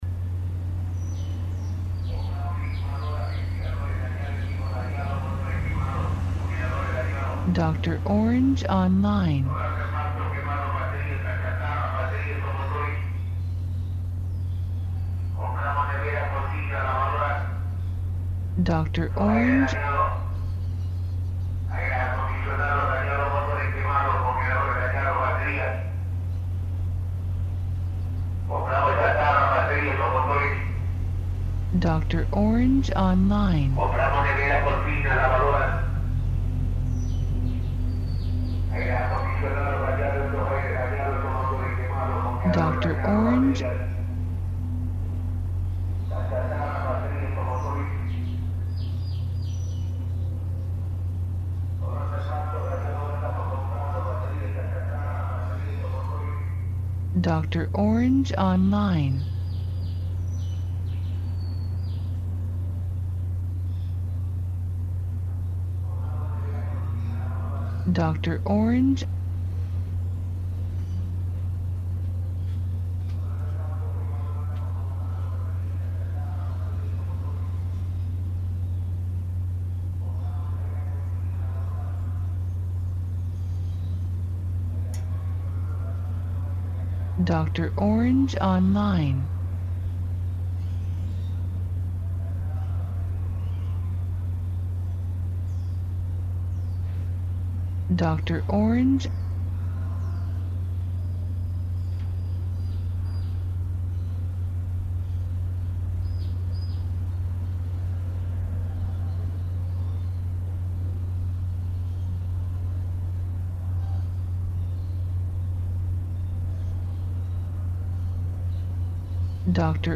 Ambiente Estéreo Interior Perifoneo Chatarrero Paranormal
INTERIOR AMBIENTE GENERICO, CHATARRERO, RECICLADOR, ACTIVIDAD PARANORMAL, AUTOS PASAN, VENDEDOR DE HELADOS GRANIZADOS, MOTO PASA.
Archivo de audio MONO, 96Khz – 24 Bits, WAV.
AMBIENTE-INTERIOR-AMBIENTE-GENERICO_CHATARRERO-96KHZ_.mp3